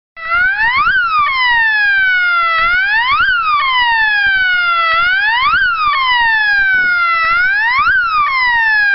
Serie: SIRENAS ELECTRÓNICAS
5 Sonidos independientes seleccionables
Gran rendimiento acústico - 110DB
Tono_4